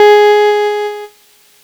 Cheese Note 02-G#2.wav